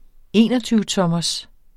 Udtale [ ˈeˀnʌtyːvəˌtʌmʌs ]